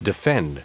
Prononciation du mot defend en anglais (fichier audio)
Prononciation du mot : defend